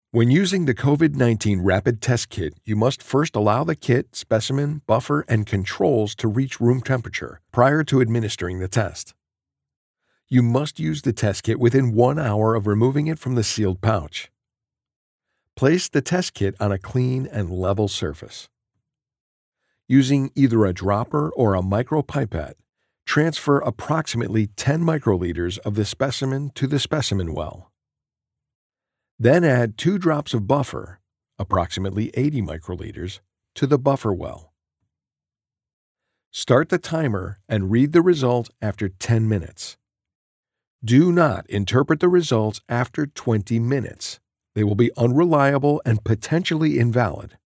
SOURCE-CONNECT Certified US MALE VOICOVER with HOME STUDIO
Medium Energy Voice-Over: Confident - Conversational - Comforting - Casual
• BOOTH: Whisper Room, acoustically-treated
medical narration